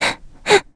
Gremory-Vox_Sad_b.wav